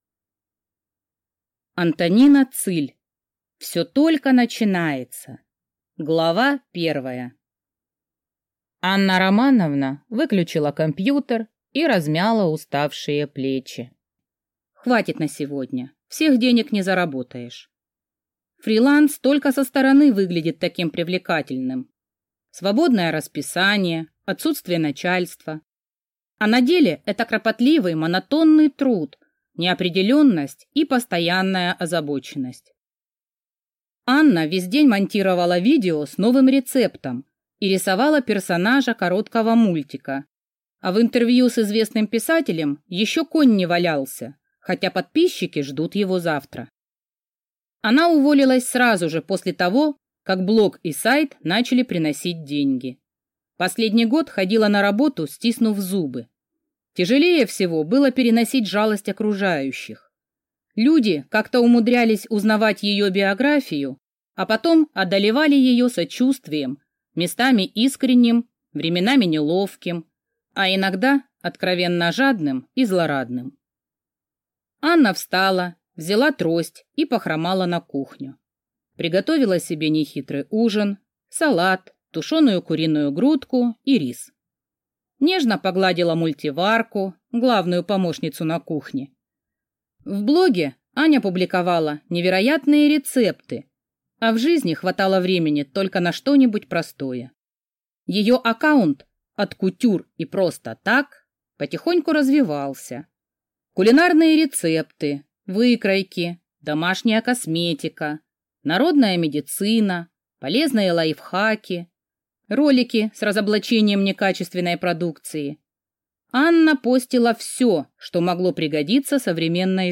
Аудиокнига Все только начинается | Библиотека аудиокниг